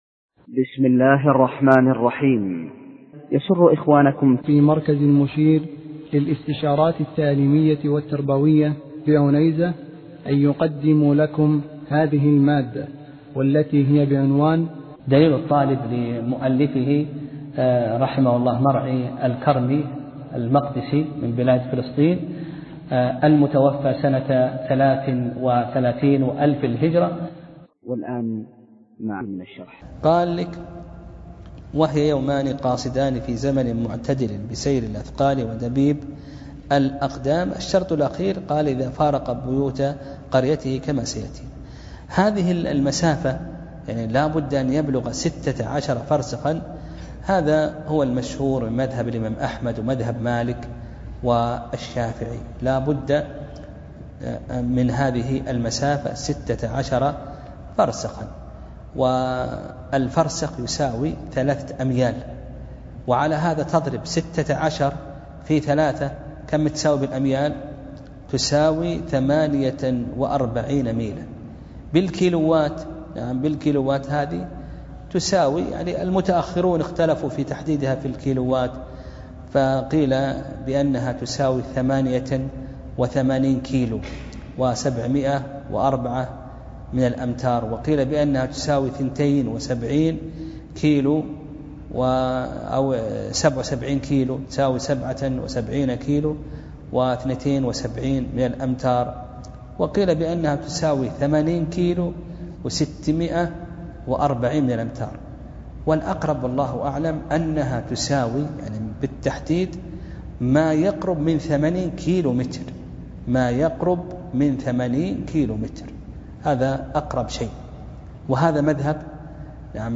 درس (15) : فصل في صلاة المسافر